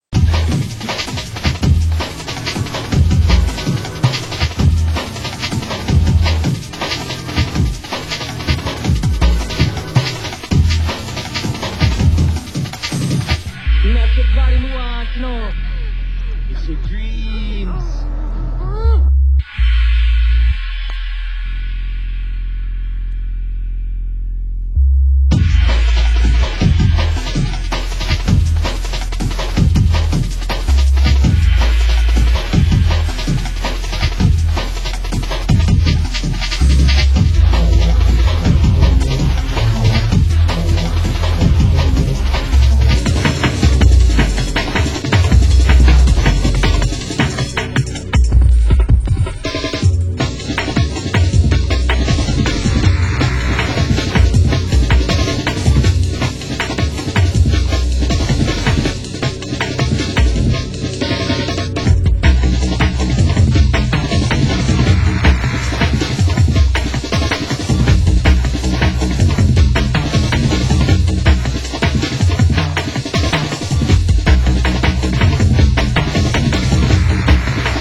Genre: Drum & Bass